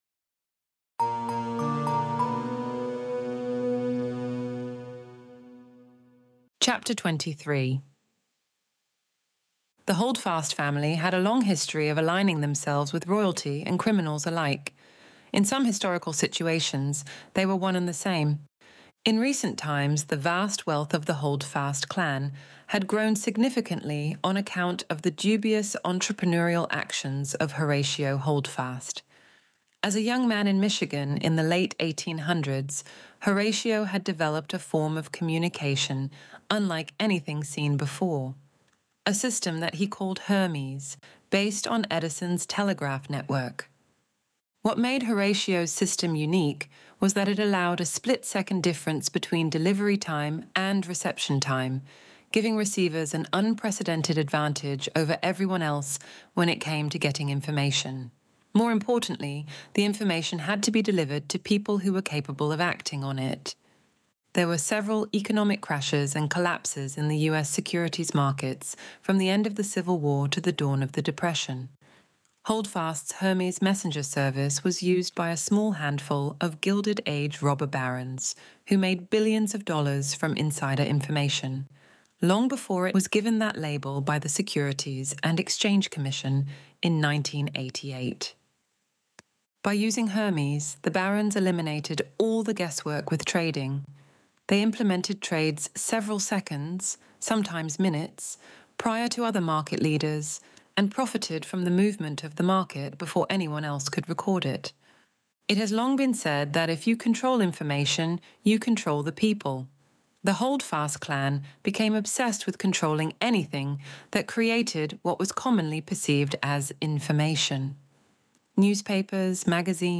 Extinction Event Audiobook Chapter 23